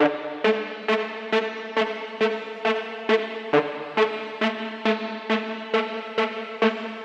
描述：用FL Studio 12. C大调。
Tag: 136 bpm Trap Loops Synth Loops 1.19 MB wav Key : C